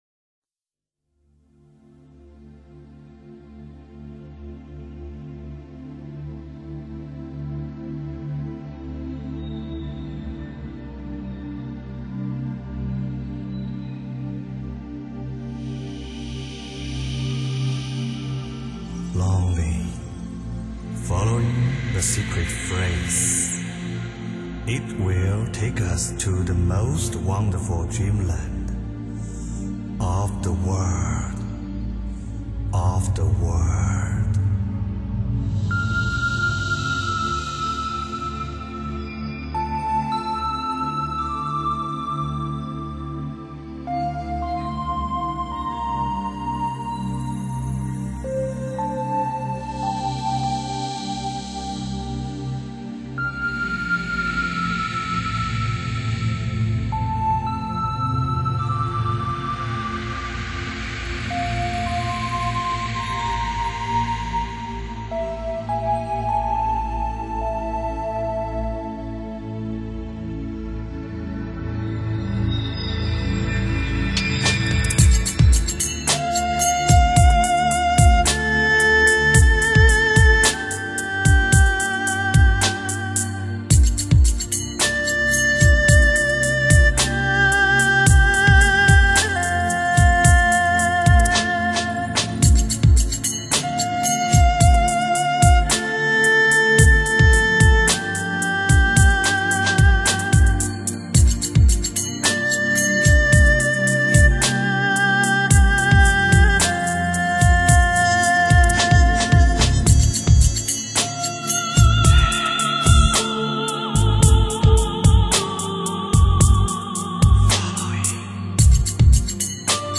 古琴
二胡
竹笛
琵琶
飘逸的二胡弦乐群，千年唐代古琴，震憾的打击鼓群，中西合壁，古今相糅，震憾和抒情交织的原创惊世之作